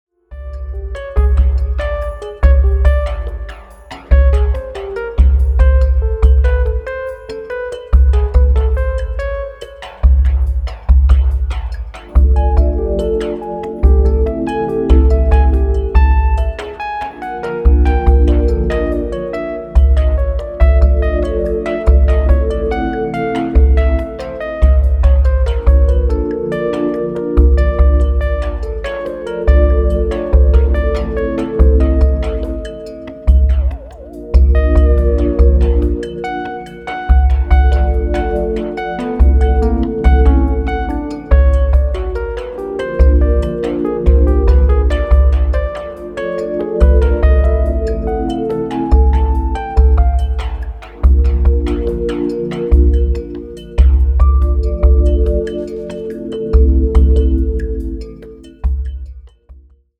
すこぶるディープで内省的なトライバル/アンビエント/クロスオーヴァーなグルーヴ。